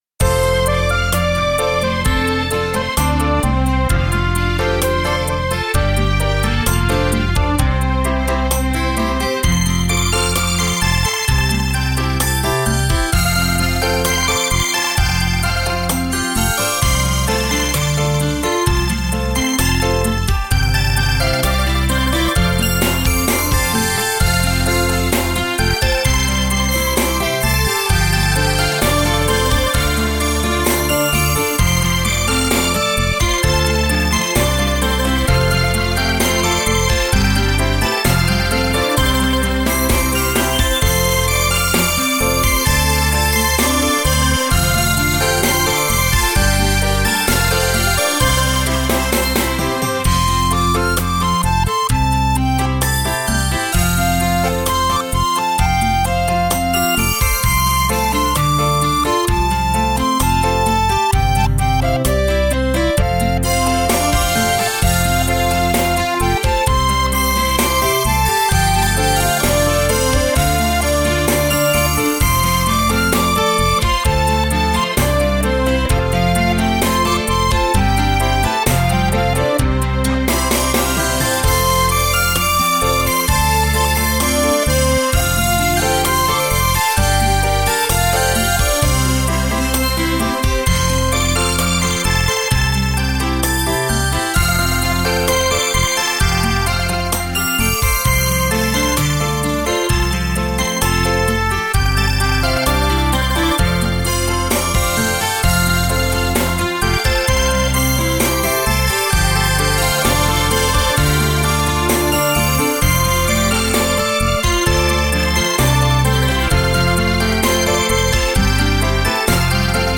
纯净晶莹的乐曲 一如似水的岁月